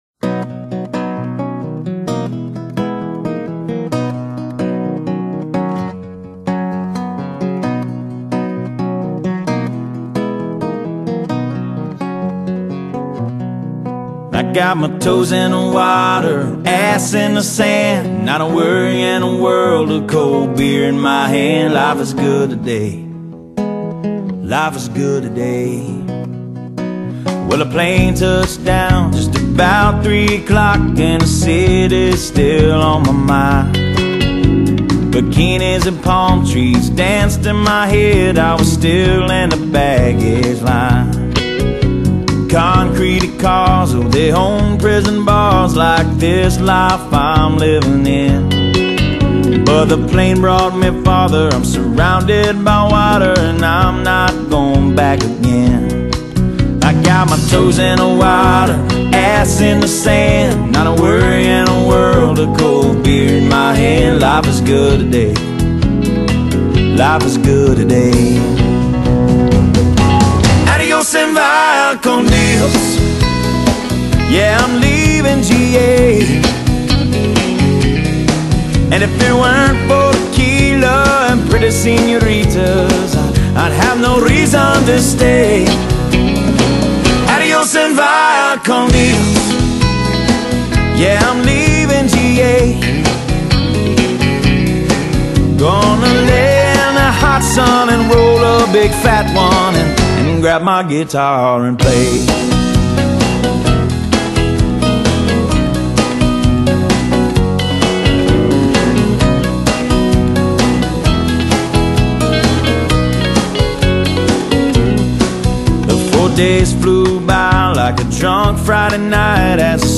他的音樂和他給別人的感覺一樣,親切,輕鬆.一份愜意隨之而來!